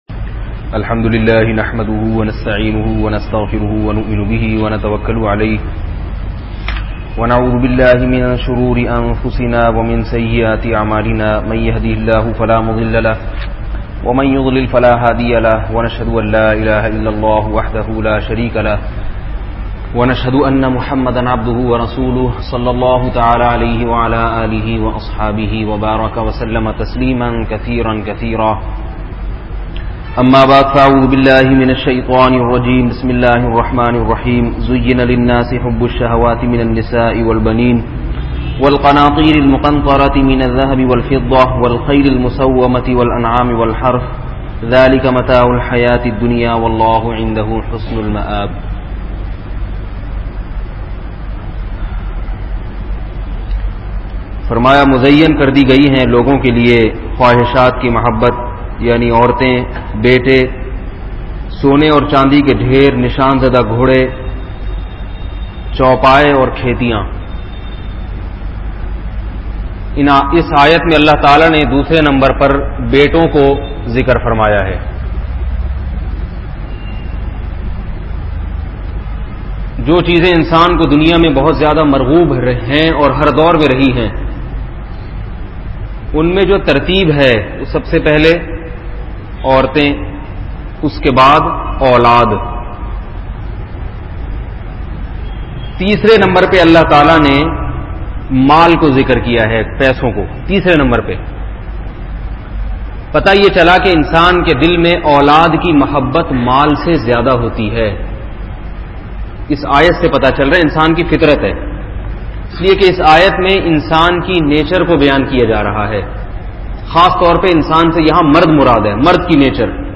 Khandani Masobabandi Kay Naam bayan mp3